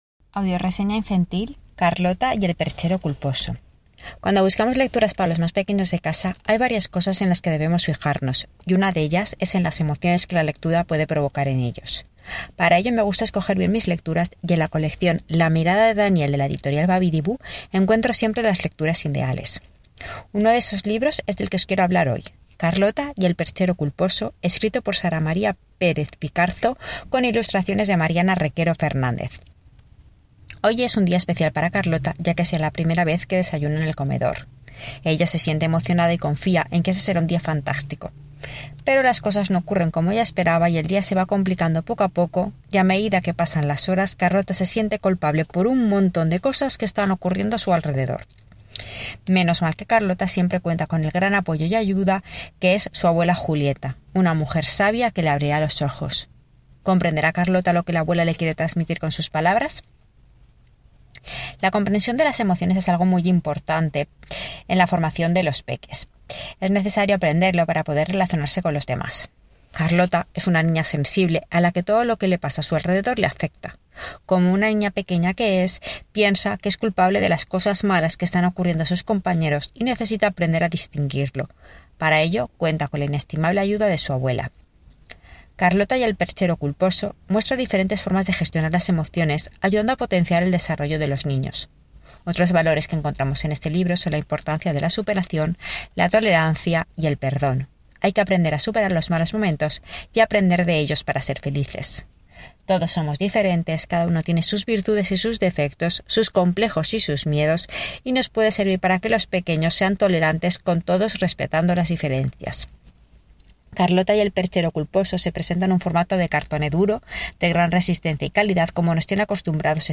AUDIO RESEÑA